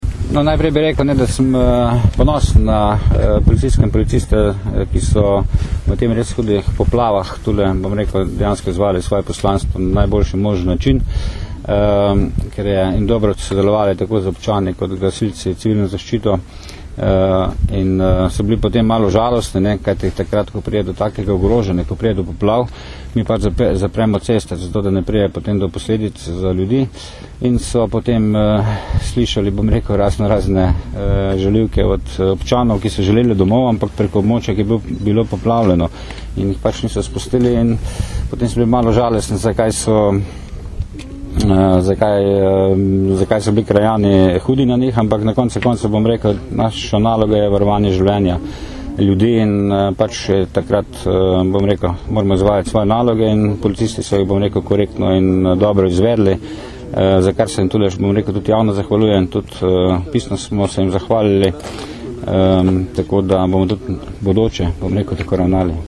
Zvočni zapis izjave generalnega direktorja policije Stanislava Venigerja (mp3)